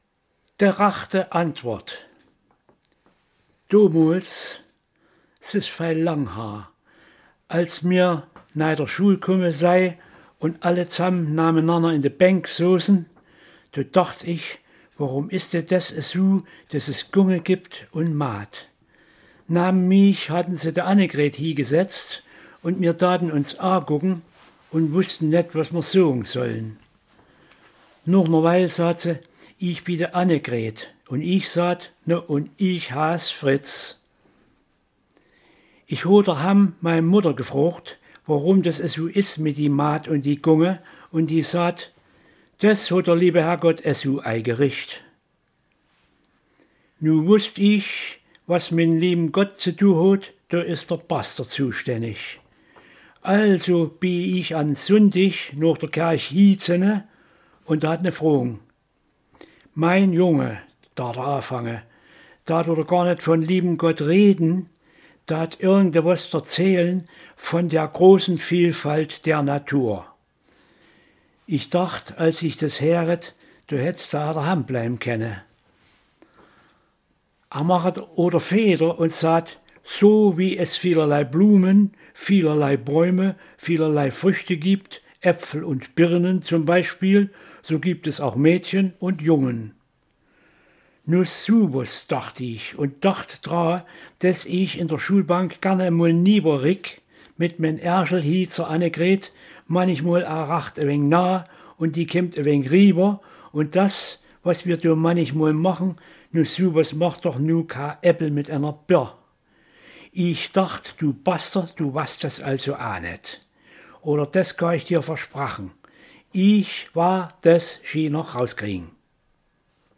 Gastbeiträge Entdecke die Vielfalt verschiedenster Dialekte – live und authentisch!
In diesem Abschnitt präsentieren wir Ihnen nach und nach eine spannende Sammlung von Audiodateien, die die verschiedenen Dialekte außerhalb unserer Region in ihrer echten, unverfälschten Form vorstellen.